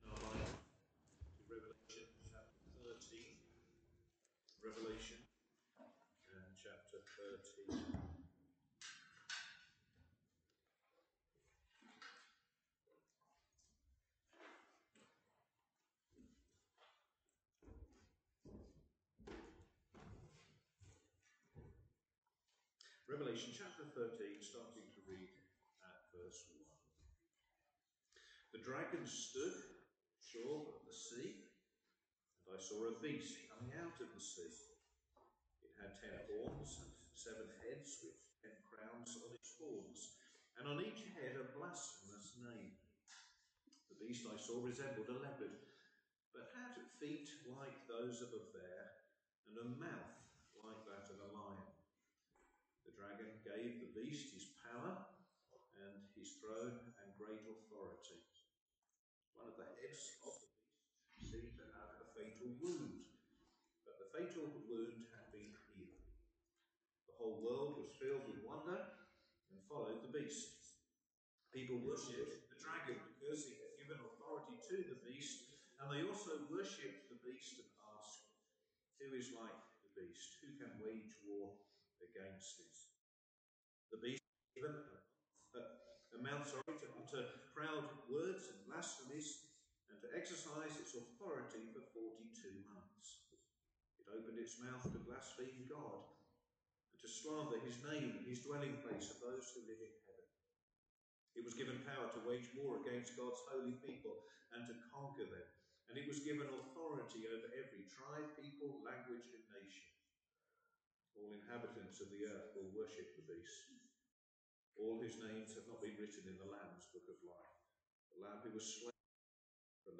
All sermons preached at Crockenhill Baptist Church